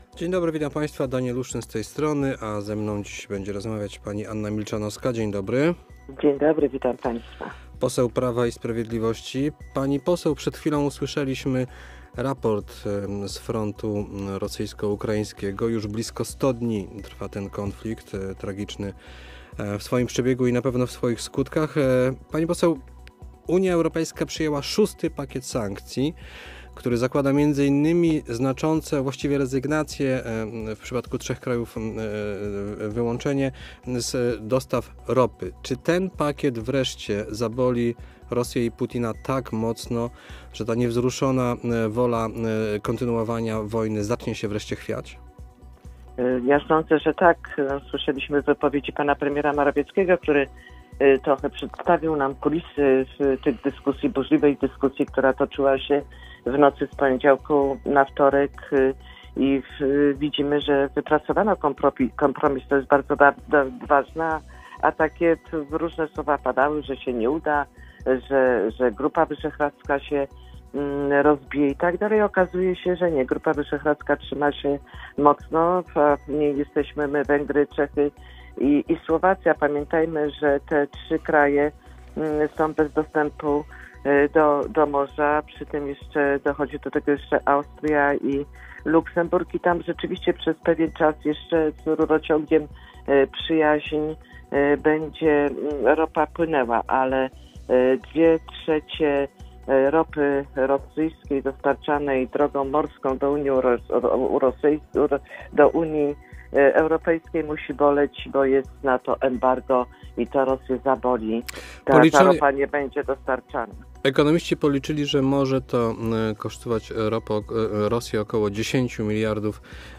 Gościem po 8 w środę (1 czerwca) była poseł Anna Milczanowska.